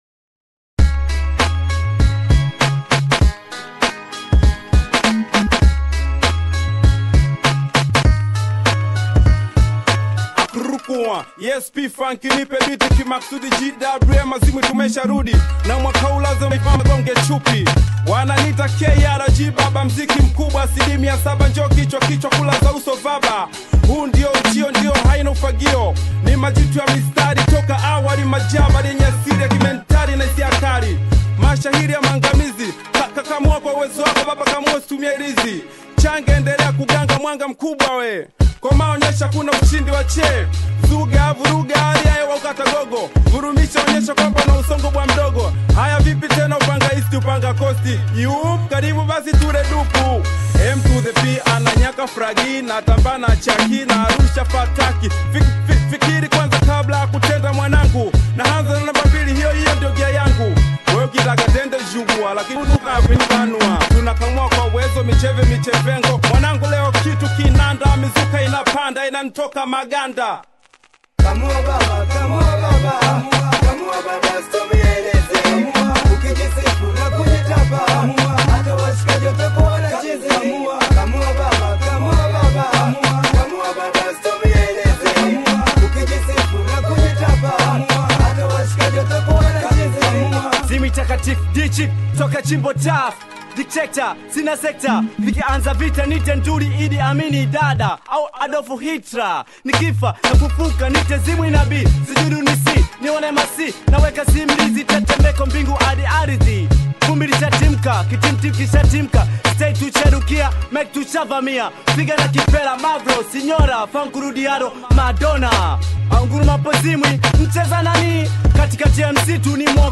Bongo flavour
beat ikiwa na kinanda/keyboard ndani